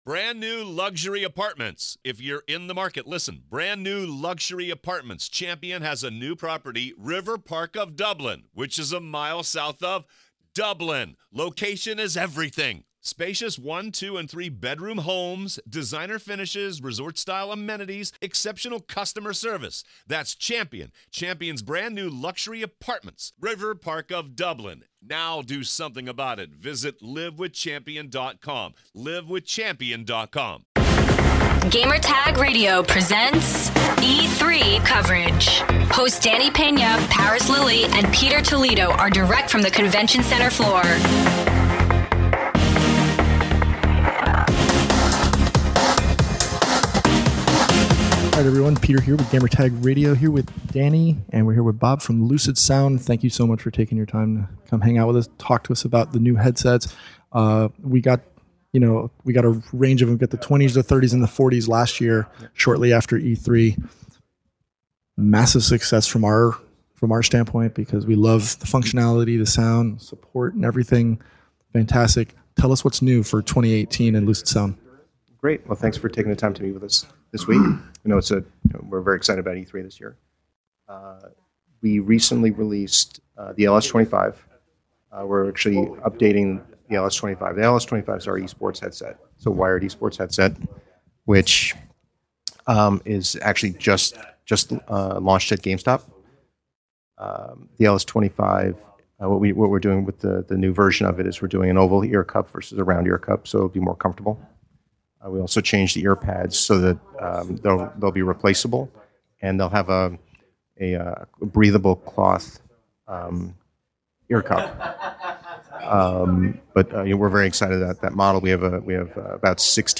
E3 2018: Lucidsound Interview